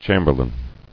[cham·ber·lain]